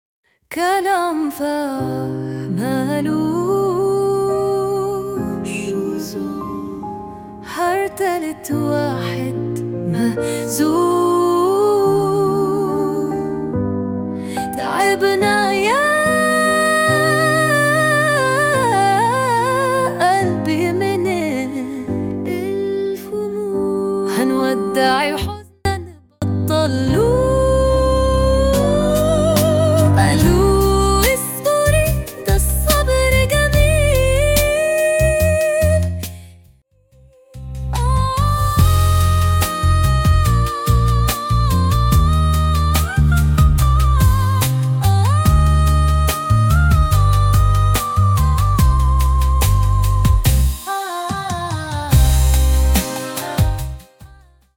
فيه ديمو مقطوش بيلفلف بصوت وحدة ثانية مش اصالة فيه هالكلام
الديمو